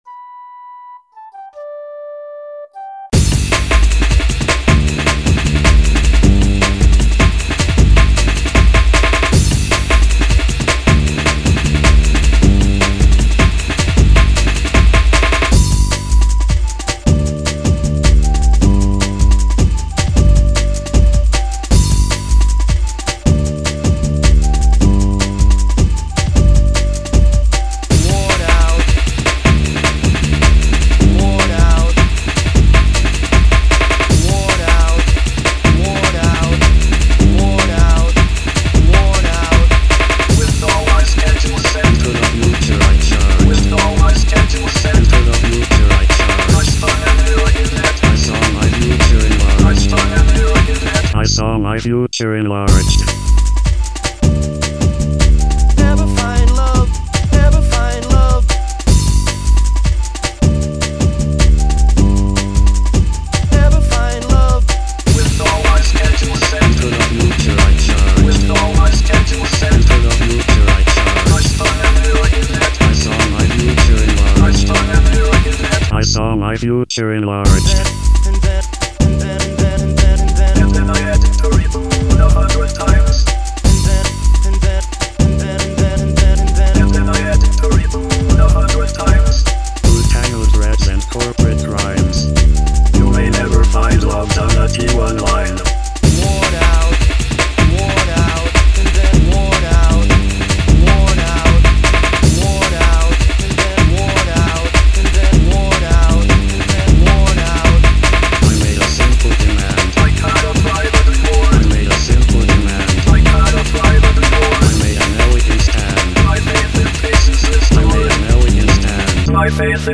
Today's random open directory find: a drum and bass influenced track accompanied by a flute. Macintalk Fred and Zarvox are used for speaking the lyrics.